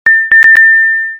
5-7.ファンファーレ（短め）
「パンパカパーン♪」と、ちょっと短めです。
fanfare.mp3